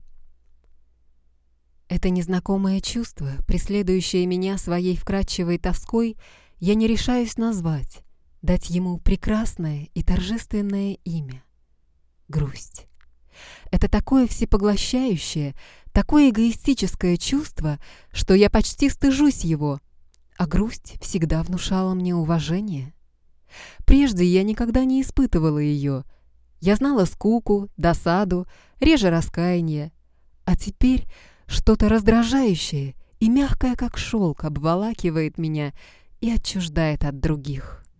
Kein Dialekt
Sprechprobe: eLearning (Muttersprache):
Can do Child, Young Female, Middle Age Female, Senior Female timbre of voice.